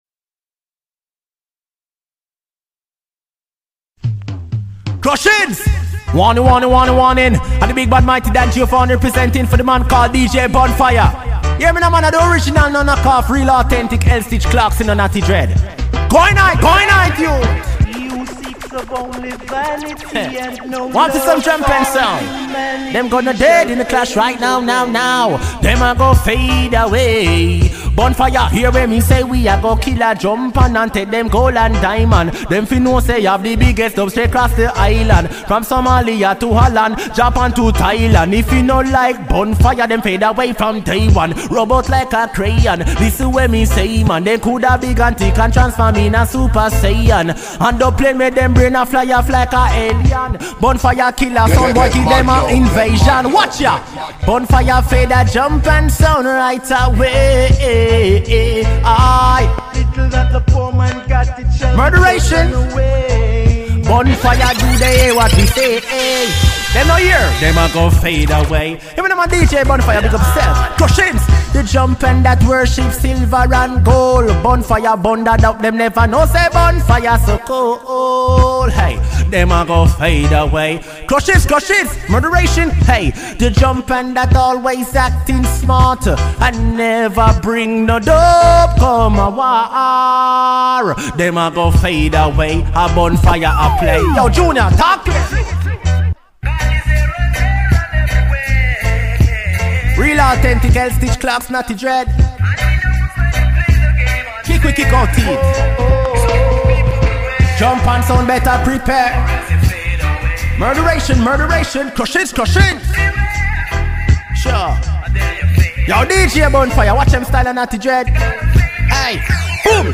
mixed irie with virtual dj pro 7